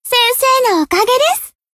贡献 ） 分类:蔚蓝档案语音 协议:Copyright 您不可以覆盖此文件。
BA_V_Nonomi_Tactic_Victory_1.ogg